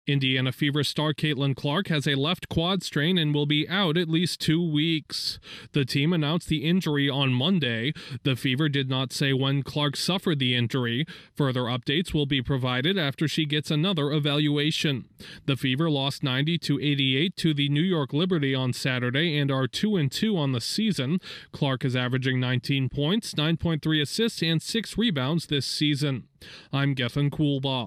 The most recognizable name in women's basketball has been sidelined by an injury. Correspondent